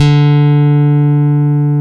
Index of /90_sSampleCDs/Roland - Rhythm Section/BS _Rock Bass/BS _Chapmn Stick